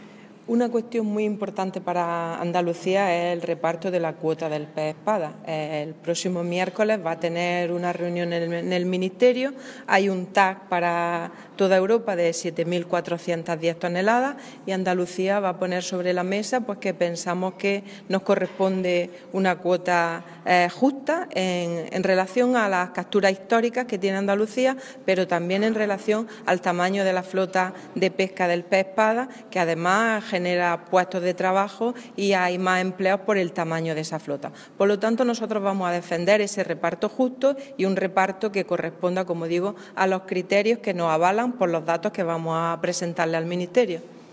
Declaraciones de Carmen Ortiz sobre gestión de la pesca del Mediterráneo